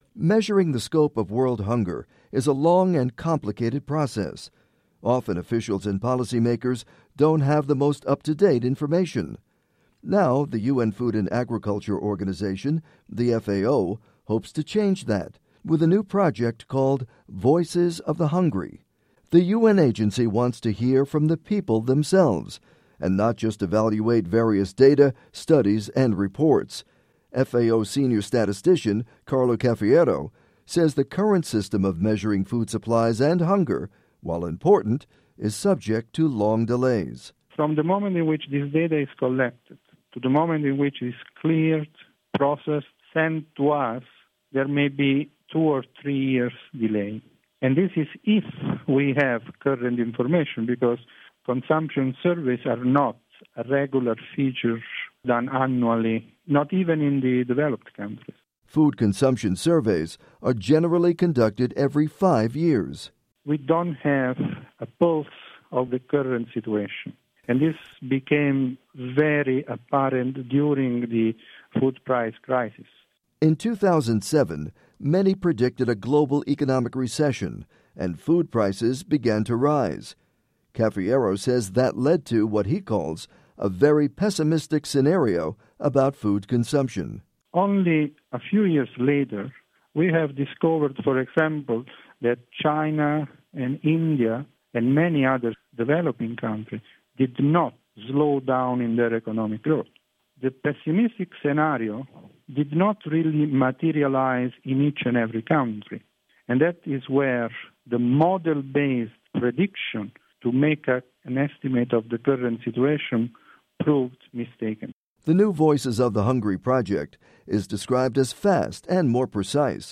report on measuring hunger